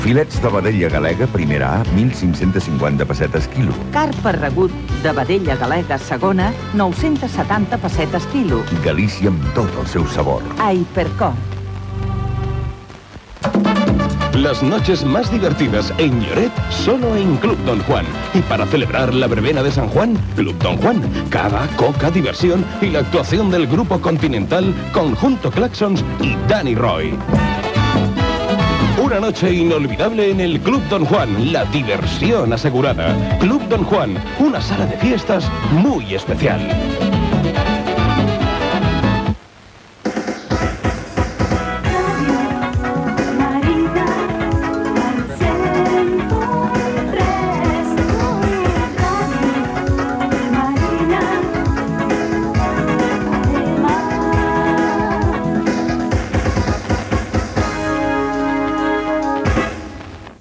6594bc18a5b5cfa534cb4f81130ccbfab2b526e5.mp3 Títol Ràdio Marina Emissora Ràdio Marina Titularitat Privada local Descripció Publicitat, indicatiu de l'emissora.